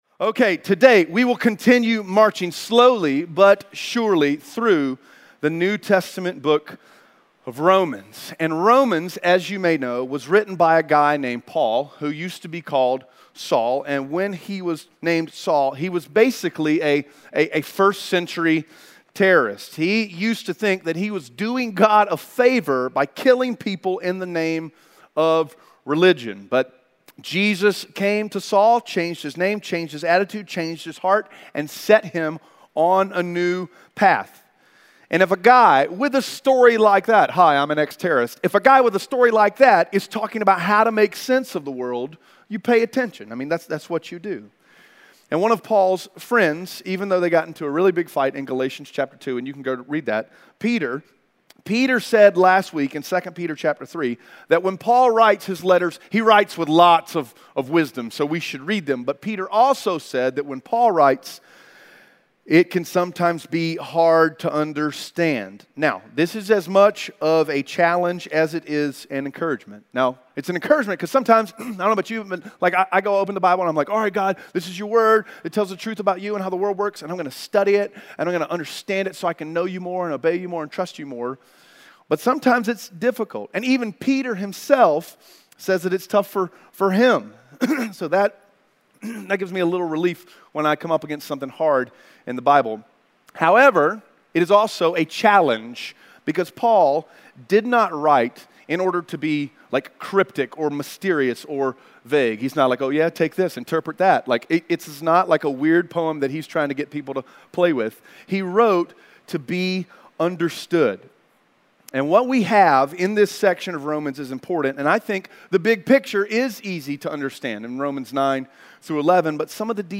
Romans 9:6-13 Audio Sermon Notes (PDF) Onscreen Notes Ask a Question *We are a church located in Greenville, South Carolina.